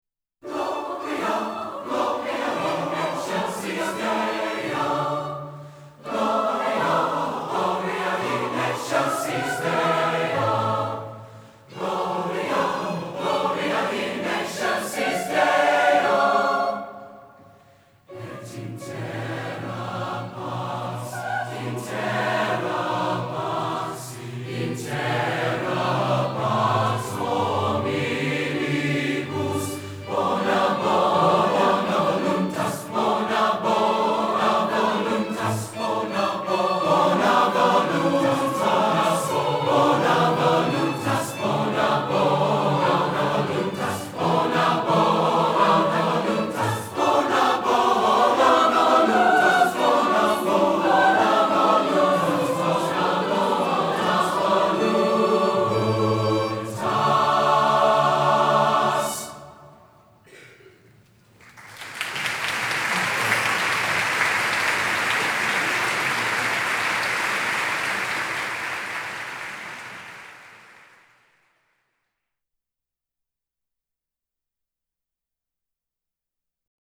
Chorus - 01 - Gloria.mp3